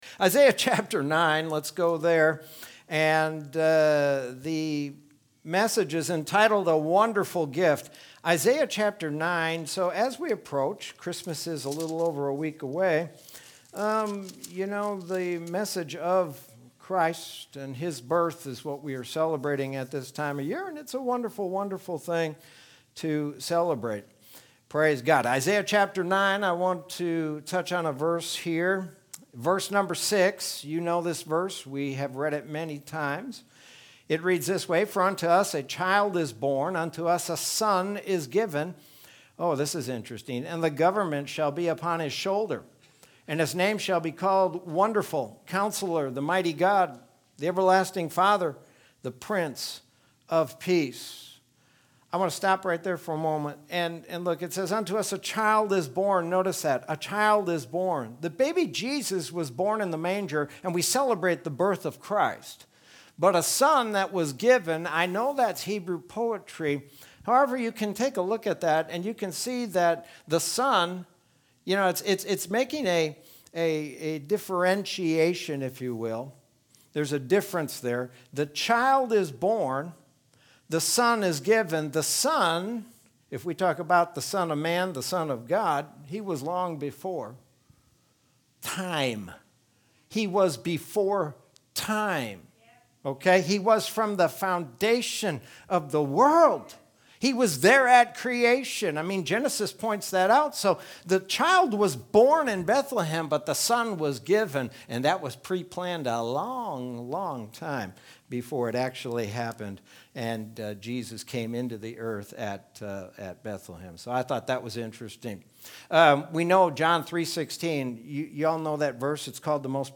Sermon from Wednesday, December 16th, 2020.